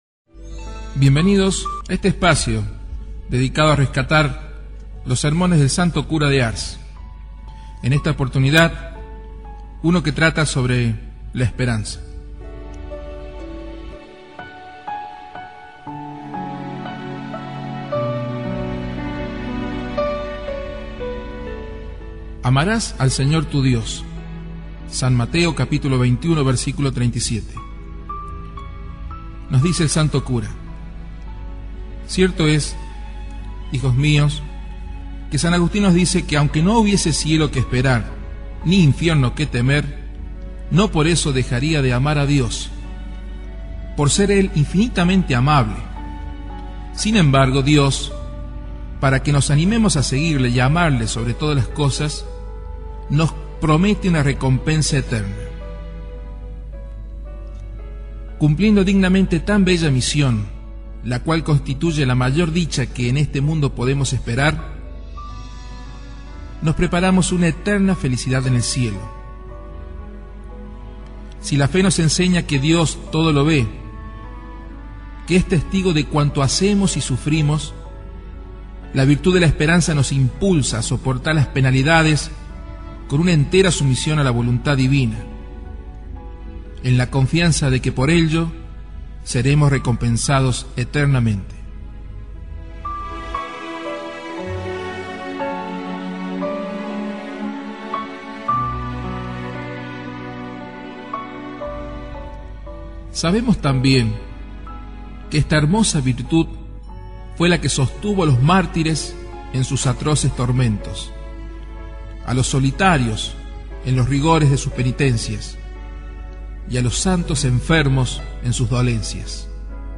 Audio–libro
Sermon-del-Santo-Cura-de-Ars-La-esperanza.mp3